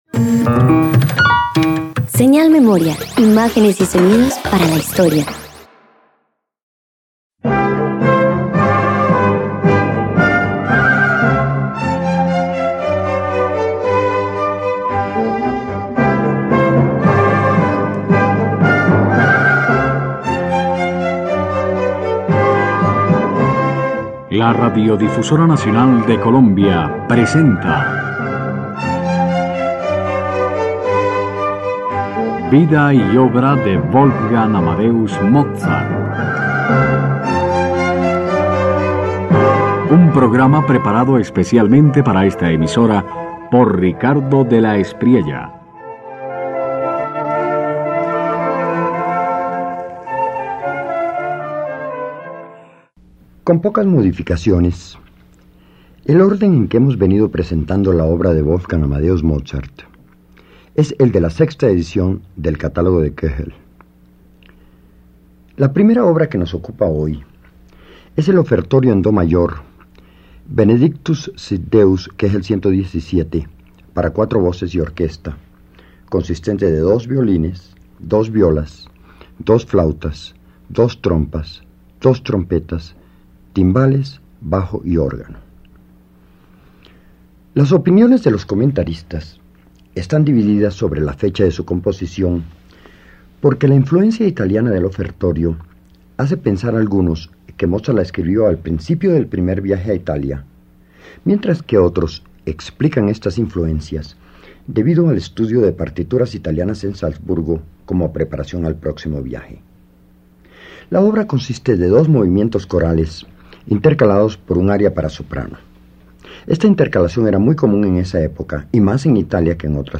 Mozart convierte el “Benedictus” en una obra donde las voces se entrelazan con ternura y firmeza, organizadas en un tejido polifónico, transparente y ordenado. La liturgia adquiere un carácter humano y cercano, que se abre como oración sincera.